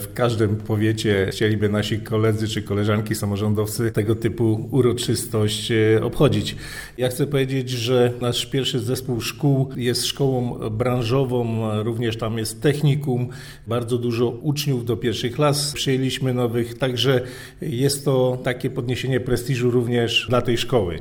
– Jest to dla nas duże wyróżnienie – powiedział Waldemar Starosta wschowski wicestarosta.